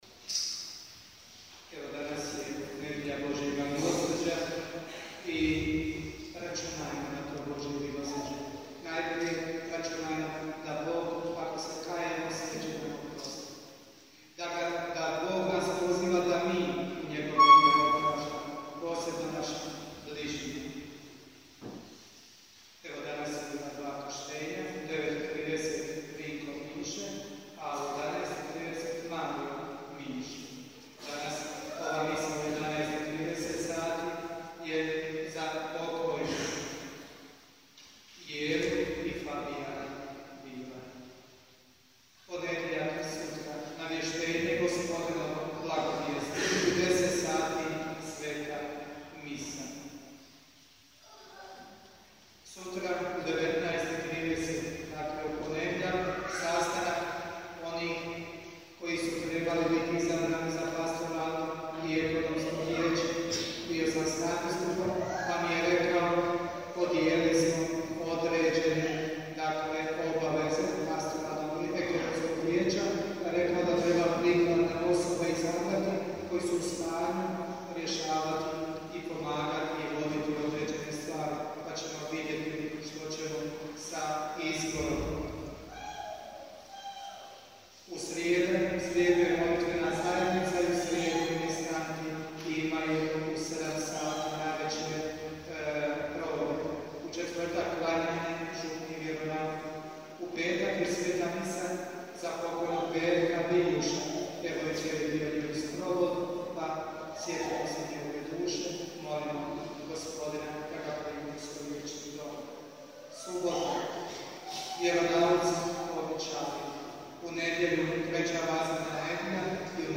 OBAVIJESTI I BLAGOSLOV – 2. VAZMENA NEDJELJA